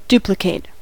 duplicate-verb: Wikimedia Commons US English Pronunciations
En-us-duplicate-verb.WAV